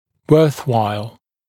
[ˌwɜːθ’waɪl][ˌуё:с’уайл]стоящий